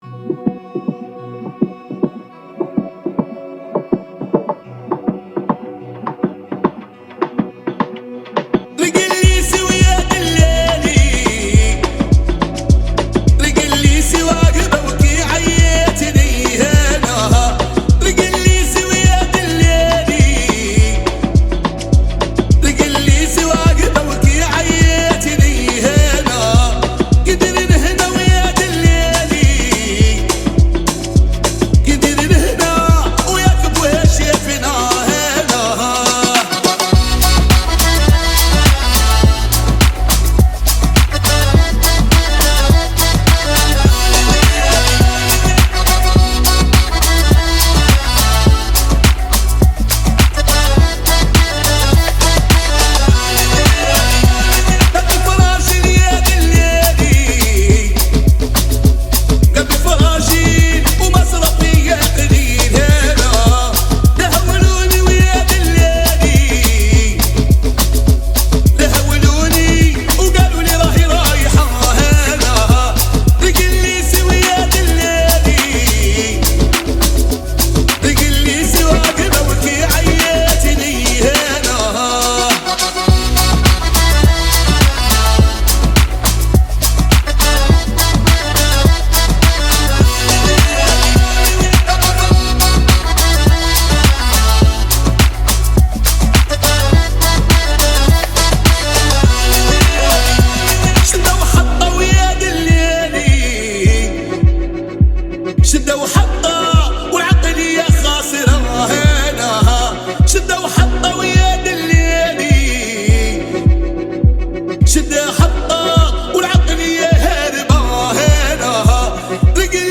(ریمیکس)
آهنگ عربی